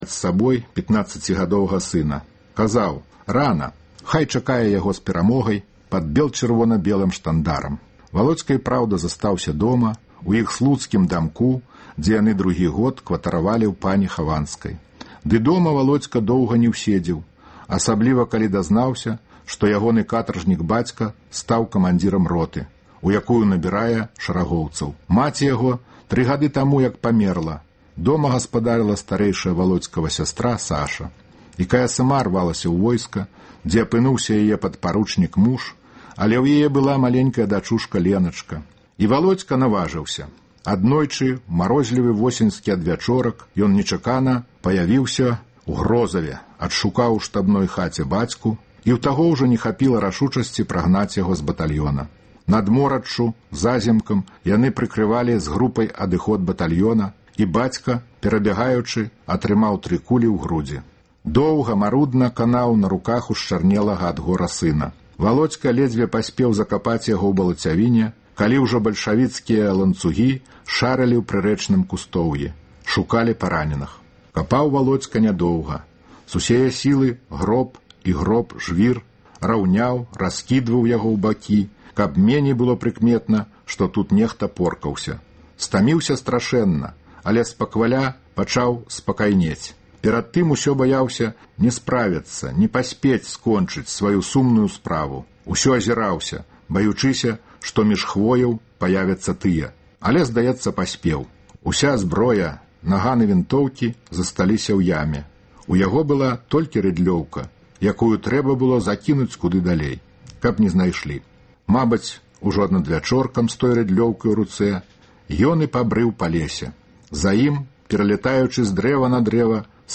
Вядомыя людзі Беларусі чытаюць свае ўлюбёныя творы Васіля Быкава. Сяргей Законьнікаў чытае ўрывак з апавядаеньня Васіля Быкава “На чорных лядах”